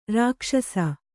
♪ rākṣasa